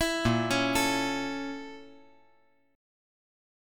Listen to A#mM7b5 strummed